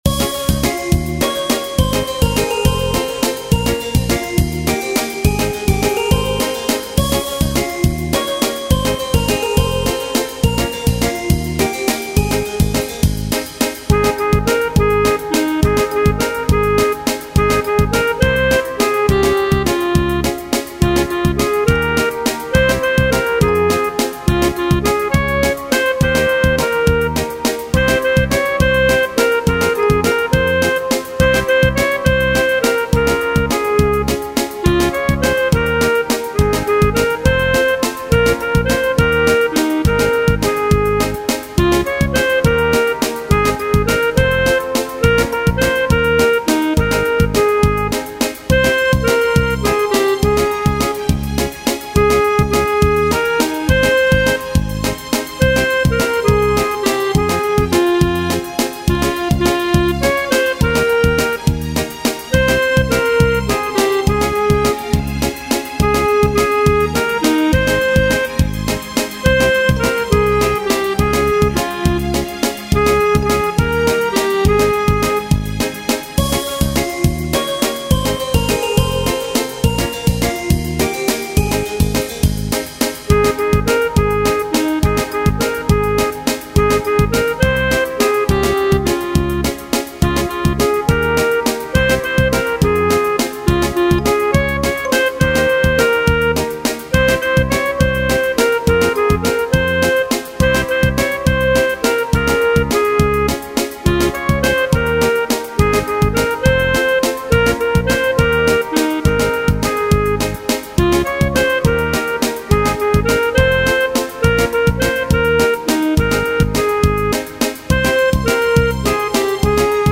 Минусовки: